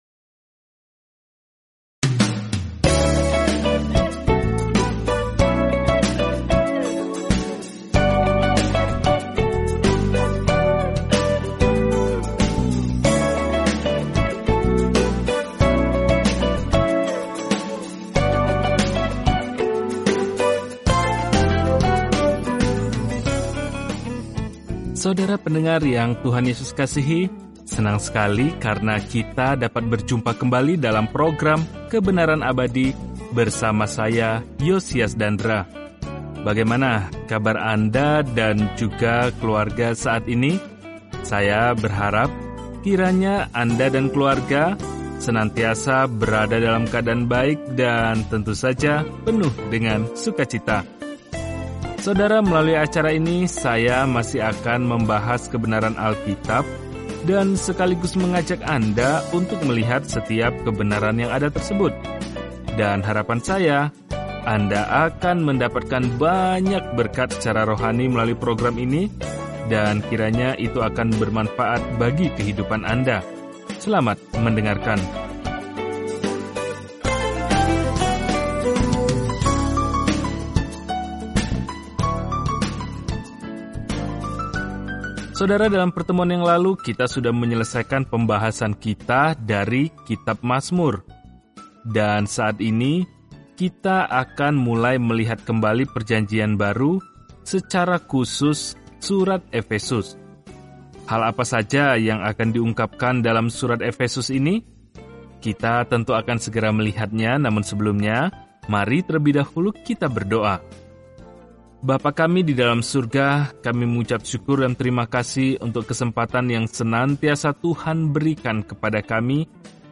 Firman Tuhan, Alkitab Efesus 1:1 Mulai Rencana ini Hari 2 Tentang Rencana ini Dari keindahan yang dikehendaki Allah bagi anak-anak-Nya, surat kepada jemaat Efesus menjelaskan bagaimana berjalan dalam kasih karunia, damai sejahtera, dan kasih Allah. Jelajahi Efesus setiap hari sambil mendengarkan pelajaran audio dan membaca ayat-ayat tertentu dari firman Tuhan.